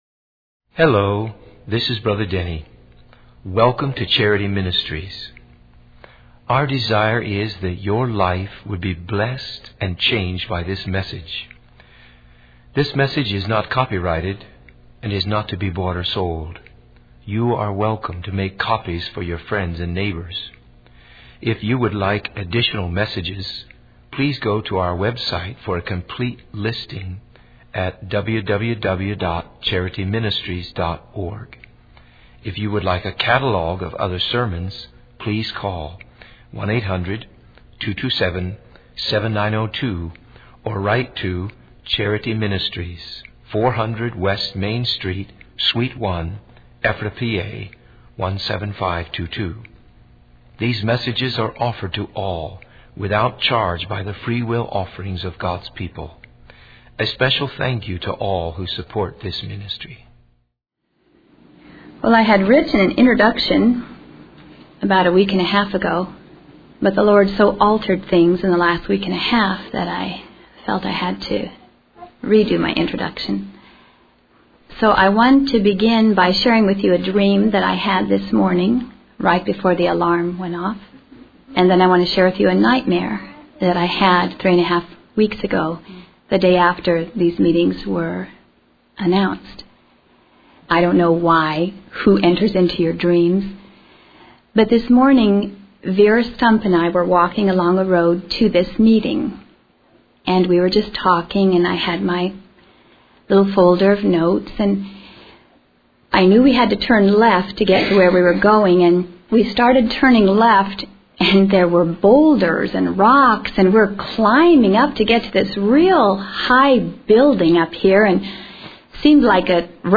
In this sermon, the speaker shares a dream and a nightmare that he had before a meeting.